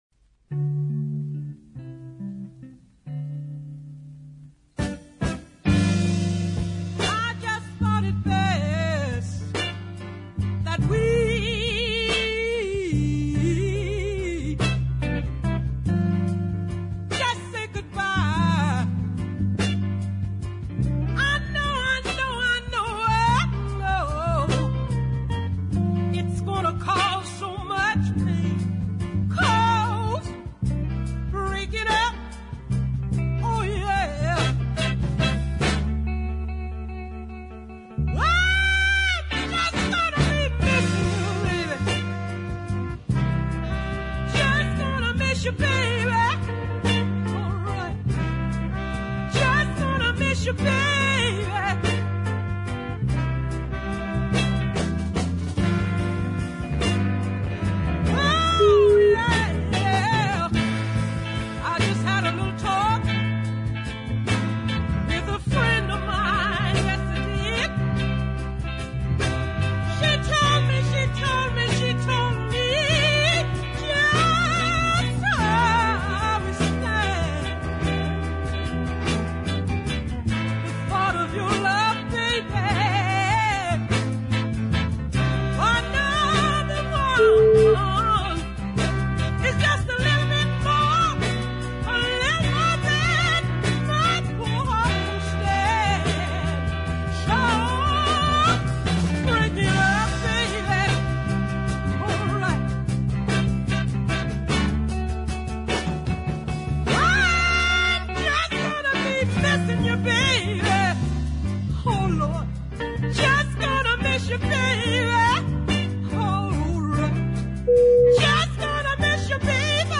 showing real gospel fire
The tasteful backing featuring some lovely chorded guitar